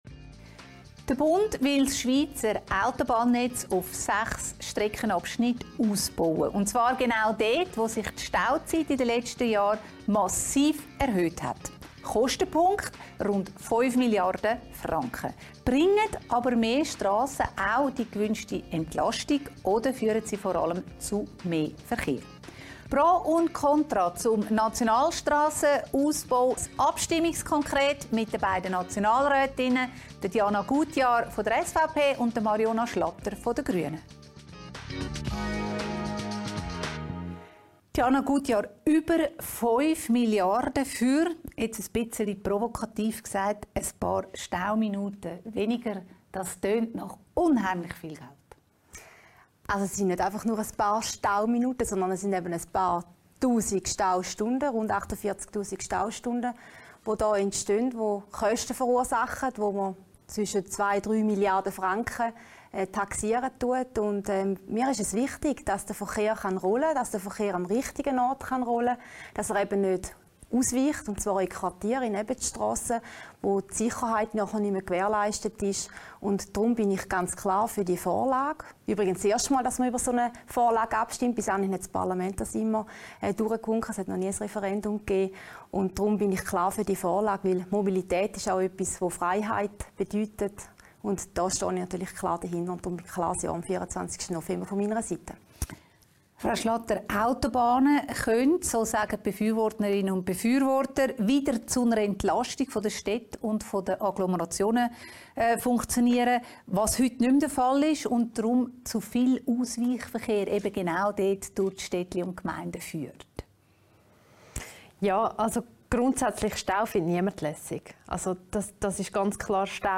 Das Abstimmungskonkret zum Nationalstrassenausbau vom 24. November mit Diana Gutjahr, Nationalrätin SVP/TG und Marionna Schlatter Grüne/ZH.